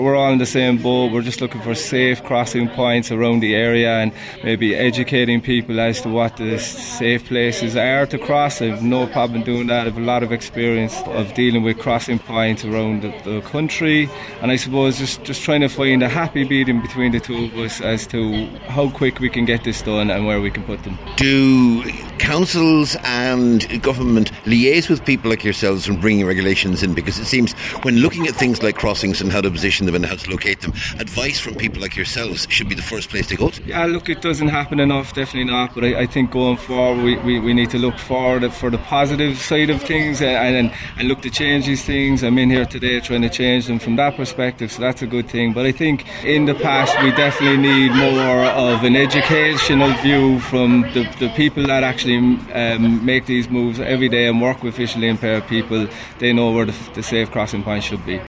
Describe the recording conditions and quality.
Speaking to Highland Radio News after the meeting